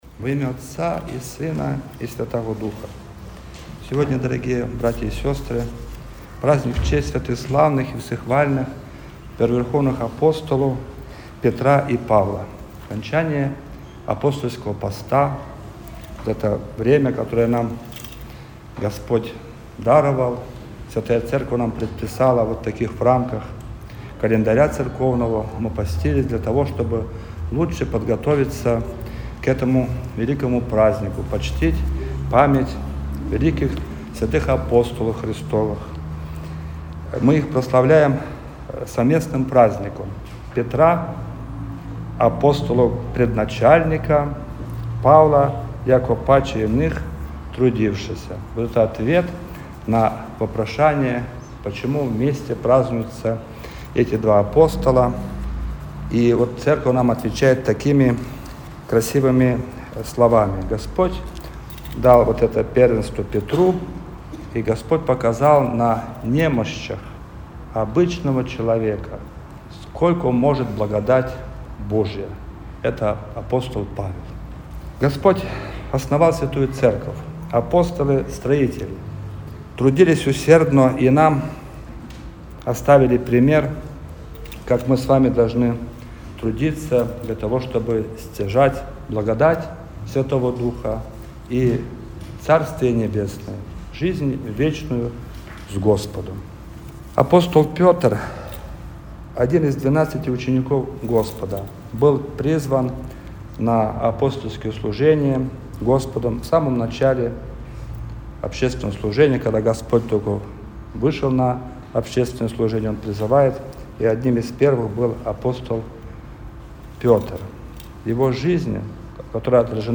Проповедь-на-праздник-апп.-Петра-и-Павла.mp3